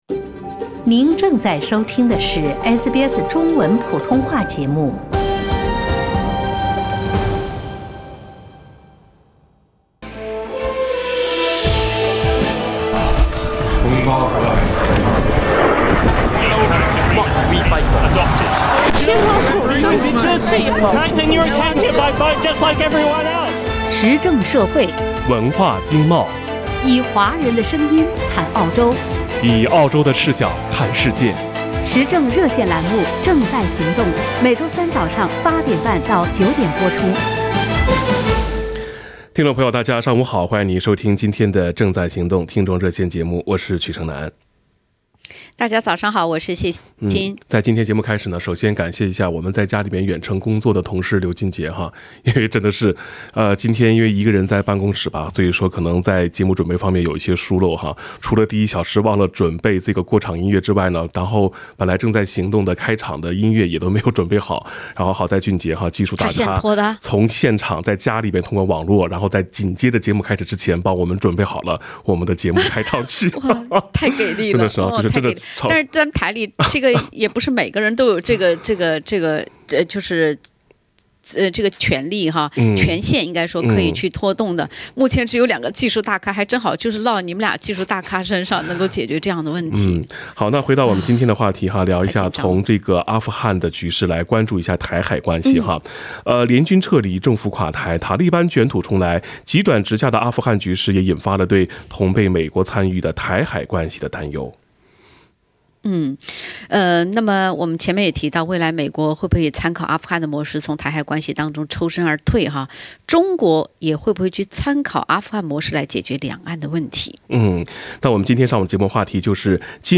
（点击封面图片，收听热线回放）